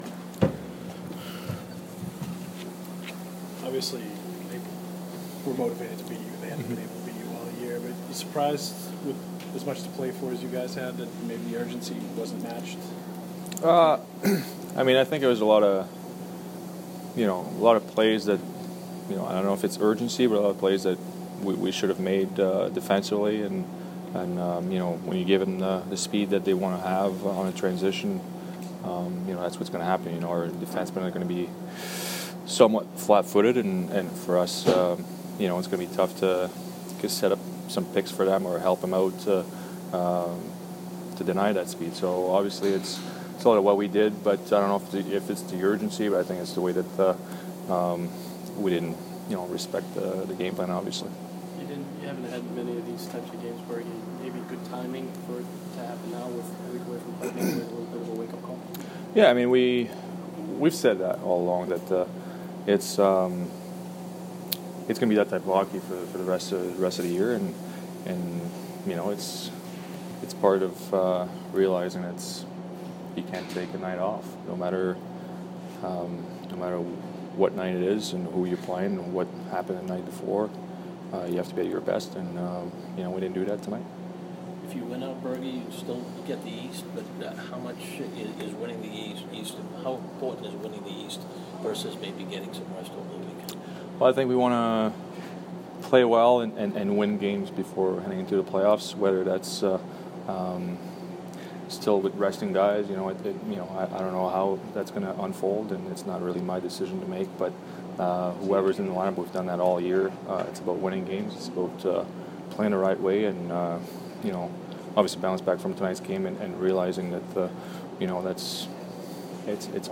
Patrice Bergeron post-game 4/3